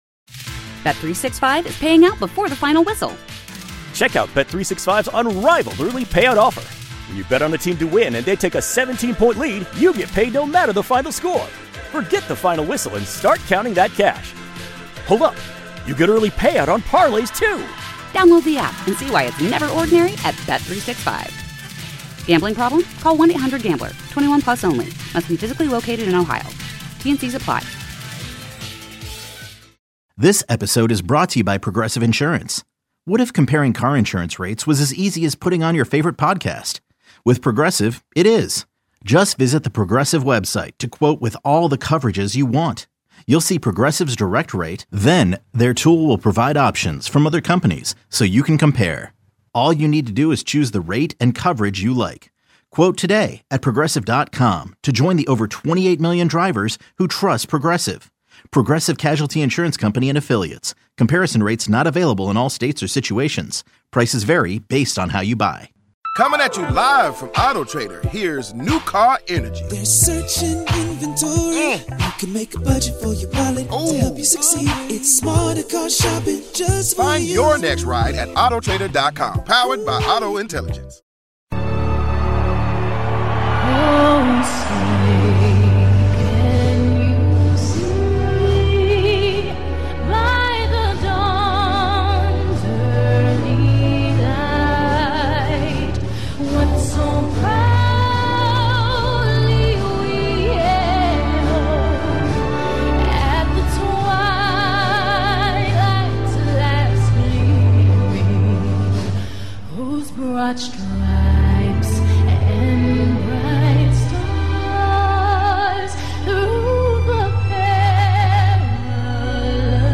National Anthem sung by Beyonce